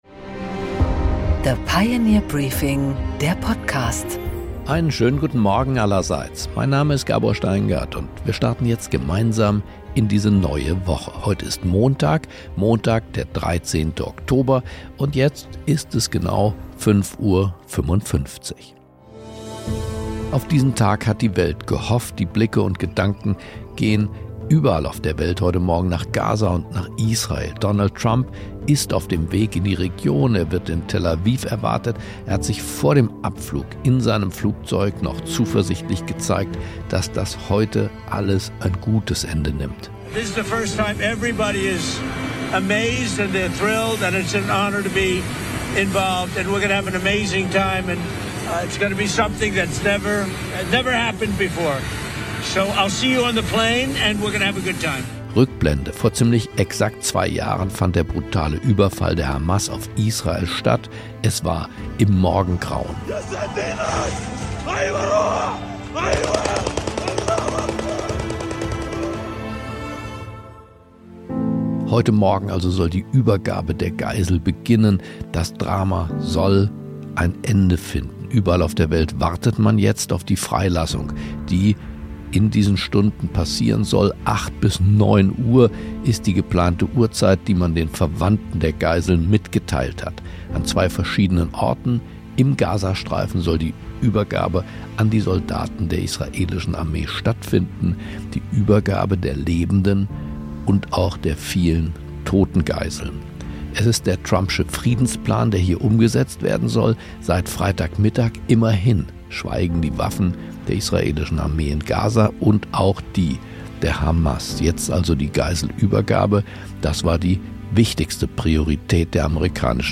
Gabor Steingart präsentiert das Pioneer Briefing
Interview mit Alexander Dobrindt